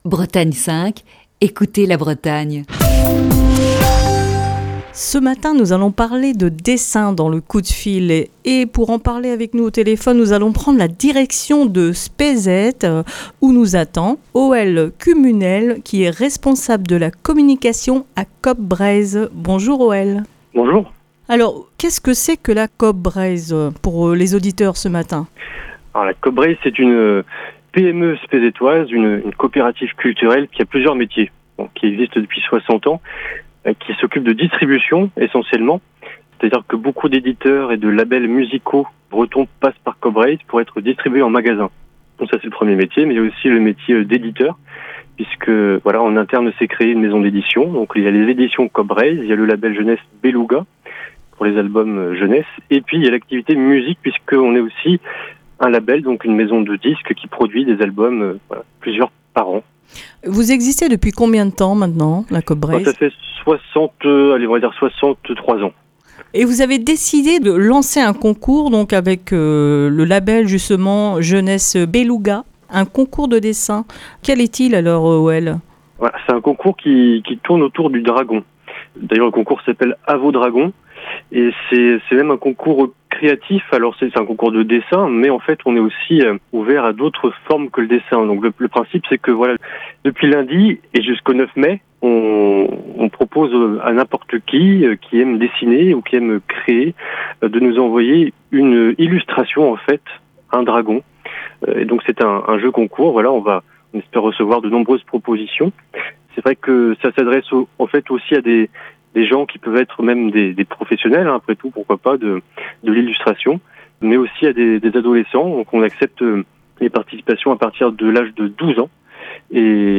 Dans le coup de fil du matin de ce mercredi